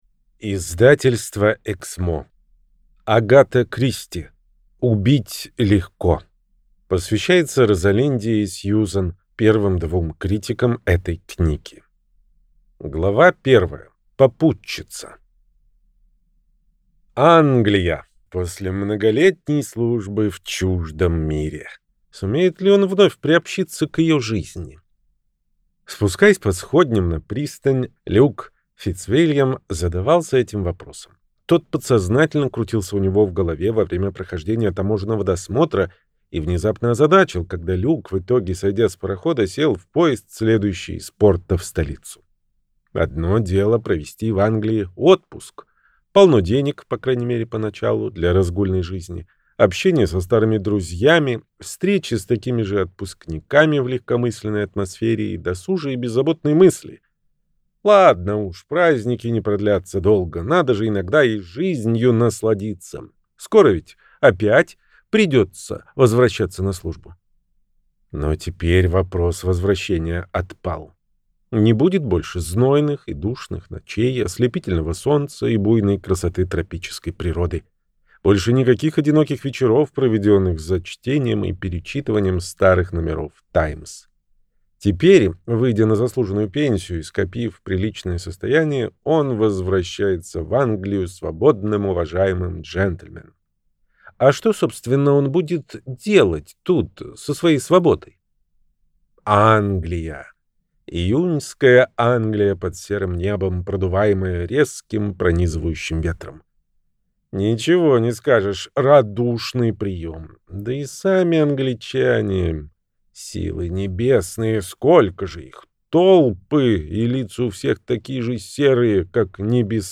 Аудиокнига Убить легко - купить, скачать и слушать онлайн | КнигоПоиск